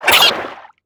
Sfx_creature_arrowray_flinch_01.ogg